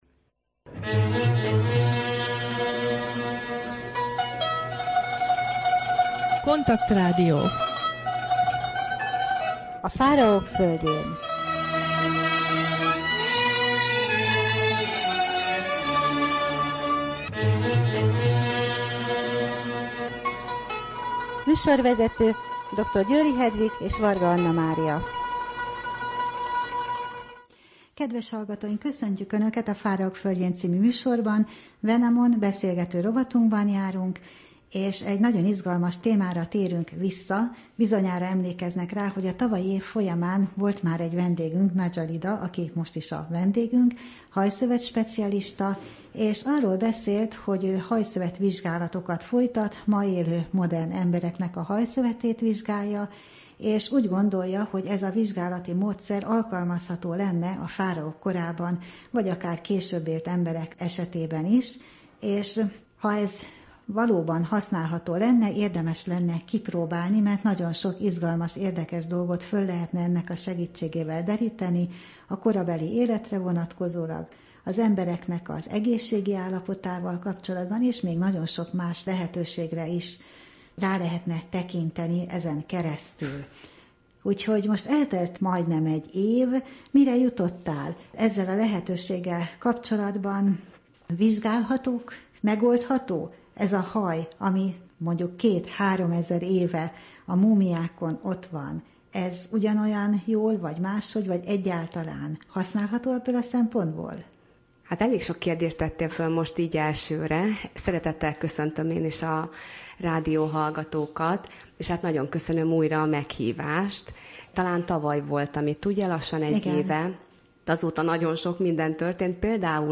Rádió: Fáraók földjén Adás dátuma: 2014, March 7 Wenamon beszélgető rovat / KONTAKT Rádió (87,6 MHz) 2014. március 7. A műsor témája Mai téma: hajvizsgálat, Ebers papirusz, lábápolás, egészségi állapot, élethossz, civilizációs betegségek, Hatsepszut, kenőcsök analízise, rák, táplálkozás, vegetáriánus étrend, múmia, konkrét vizsgálati eredmények, alumínium, Alzheimer kór.